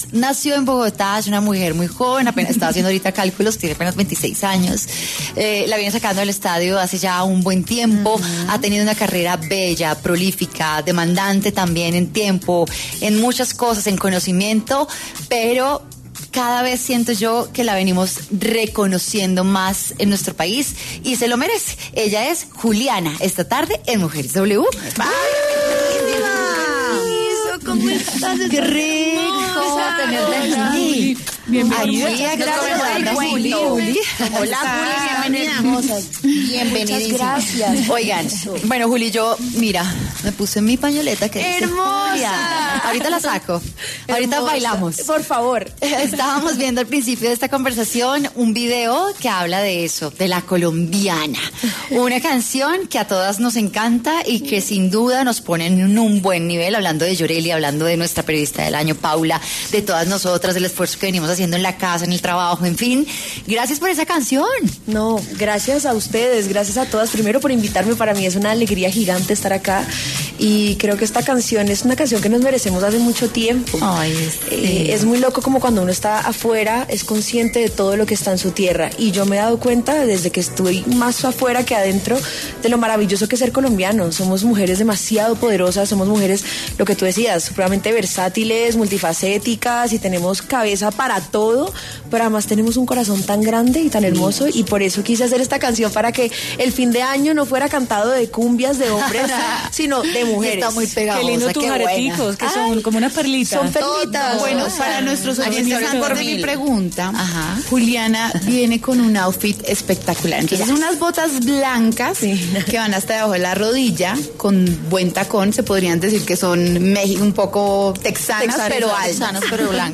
En diálogo con Mujeres W, la cantante y actriz Juliana Velásquez, mejor conocida como Juliana, compartió detalles acerca de su carrera artística.